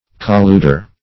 \Col*lud"er\